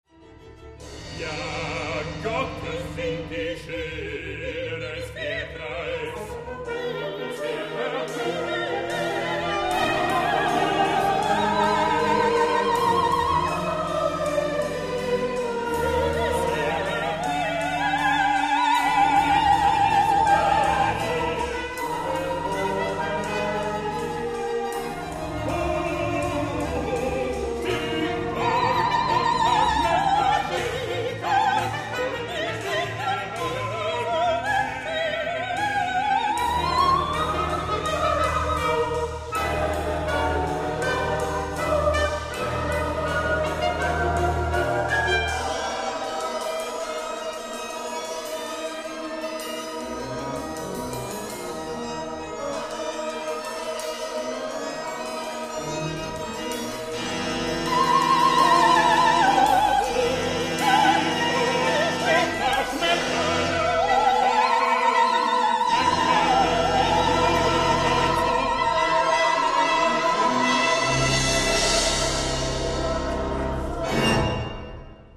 Oratorium
Uraufführung am 16.9.2001 in der Darmstädter Pauluskirche
Hörbeispiele aus dem Mitschnitt der Uraufführung:
Bass